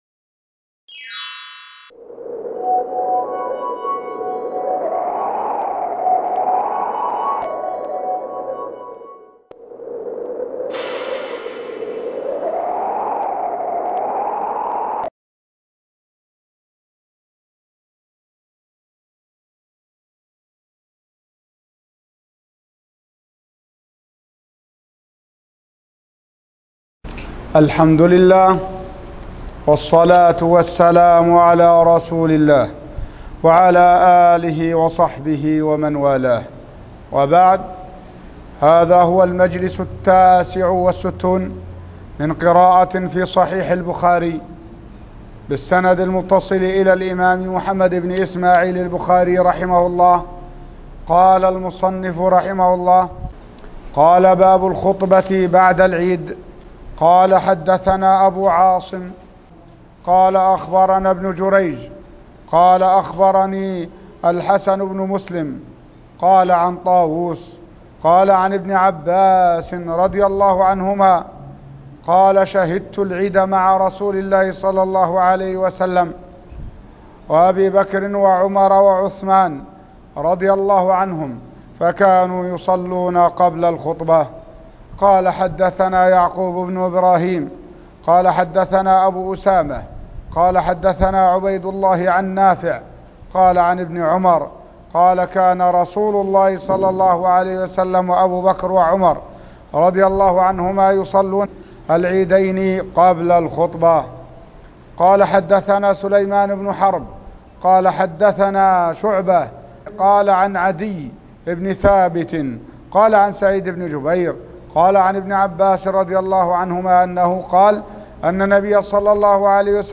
الدرس ( 69) قراءة صحيح البخاري